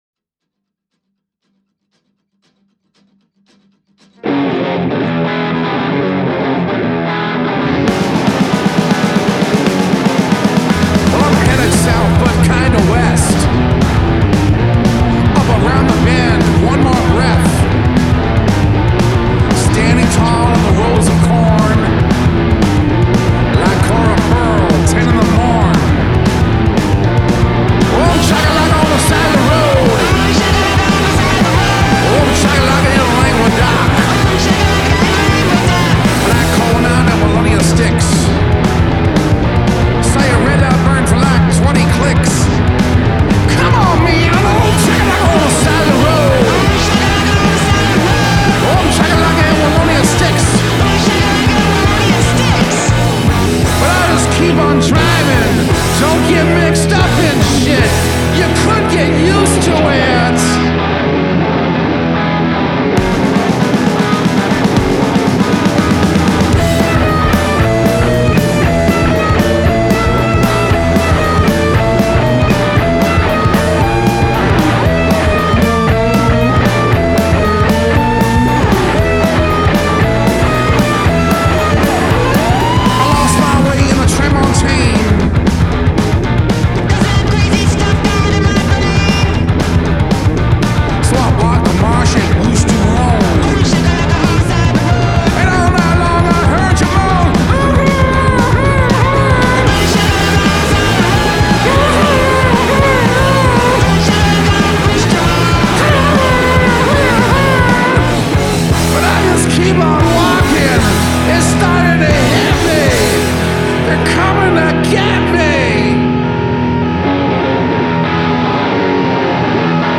signature yelps and rapid-speed delivery
fuzzed out surf-punk guitar